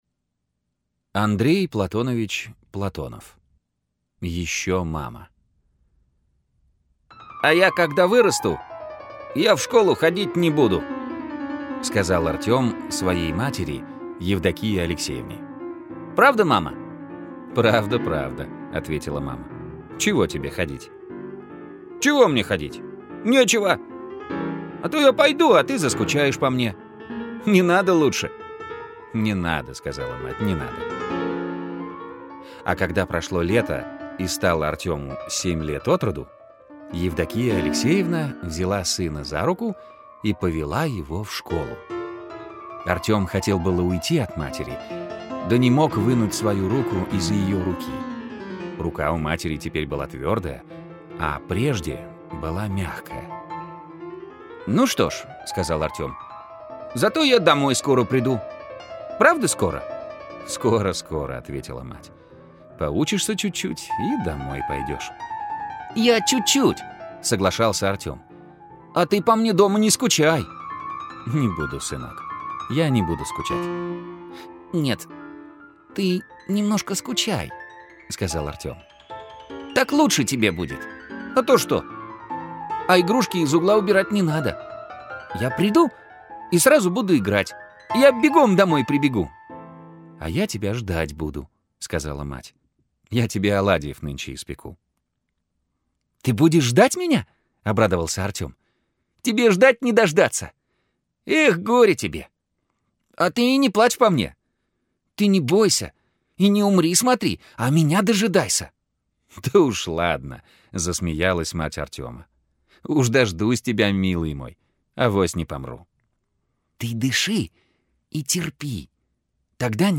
Еще мама - аудио рассказ Платонова А.П. Рассказ про мальчика Артёма, котором исполнилось семь лет и пора было идти в школу.